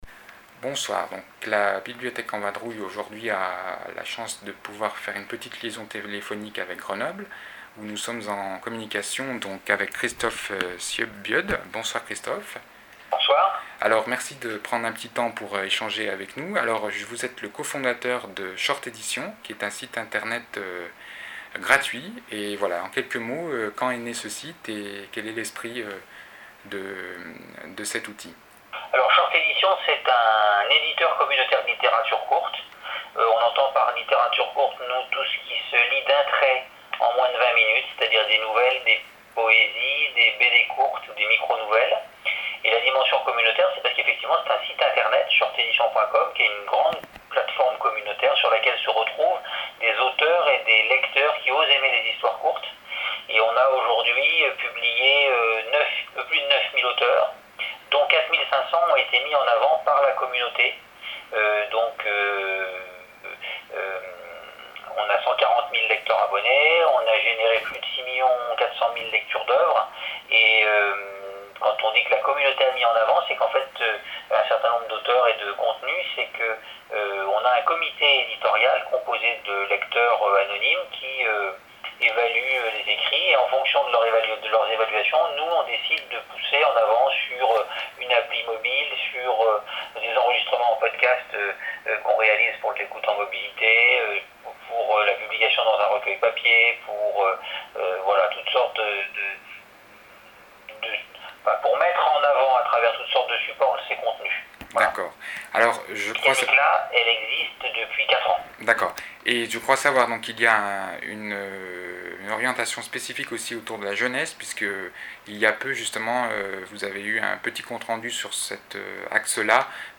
Lieu : Grenoble -> Belfort, par téléphone Date : 2 novembre 2015